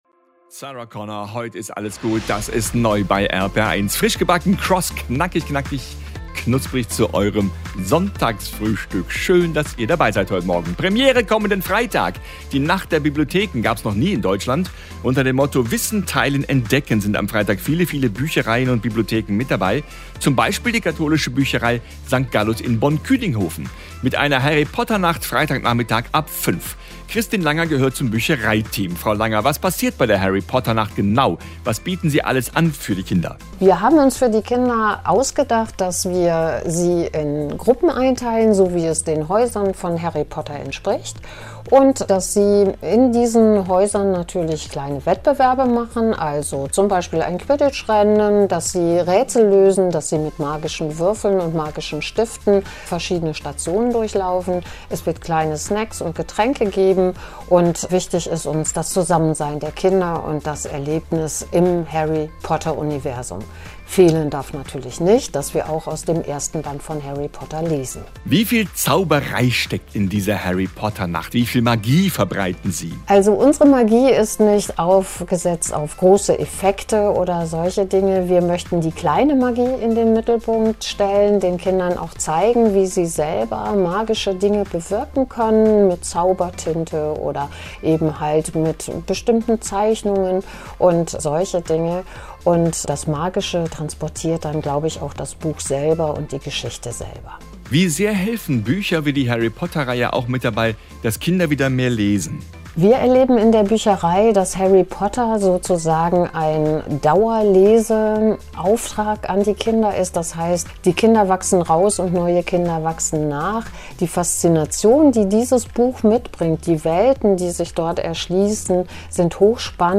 So wurde unsere Aktion im Radio angekündigt: